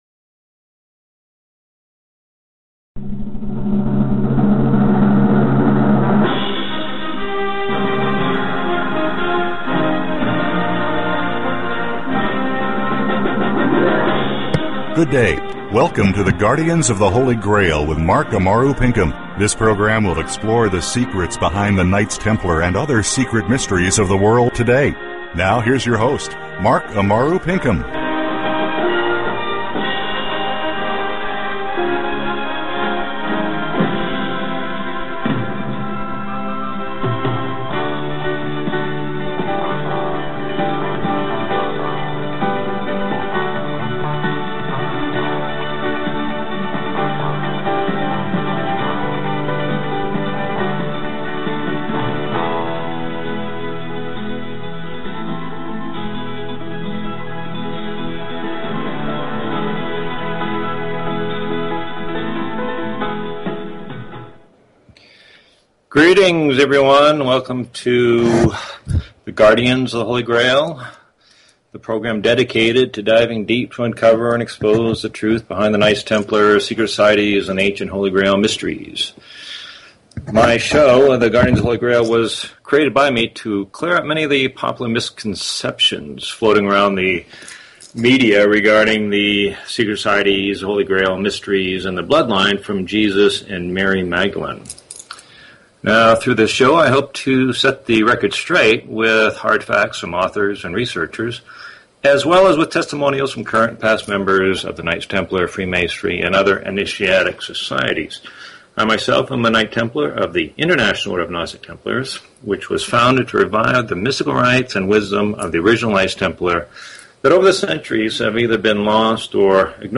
Talk Show Episode, Audio Podcast, The_Guardians_of_the_Holy_Grail and Courtesy of BBS Radio on , show guests , about , categorized as